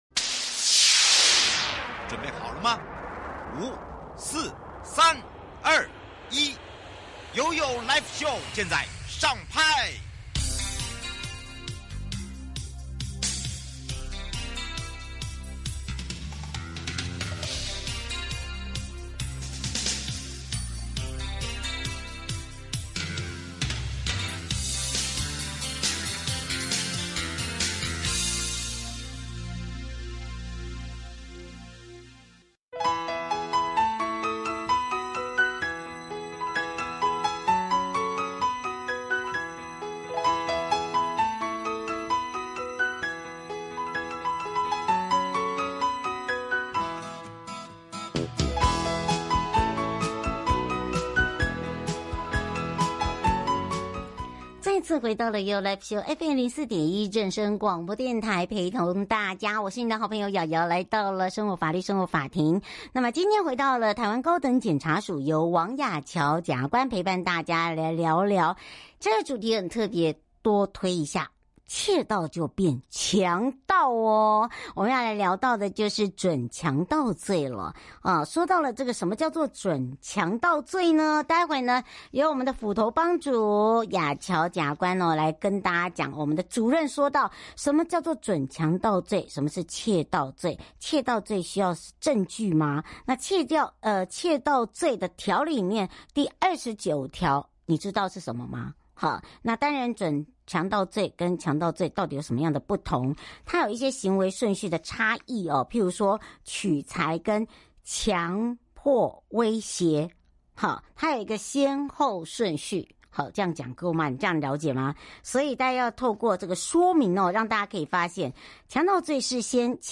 受訪者： 1. 法務部徐錫祥次長 2. 法務部鄭銘謙部長 3. 最高檢察署邢泰釗檢察總長 節目內容： 1.受刑人戒菸比賽 臺中監獄檢測暨頒獎~受刑人「2024戒菸比賽」齊聚臺中監獄檢測暨頒獎！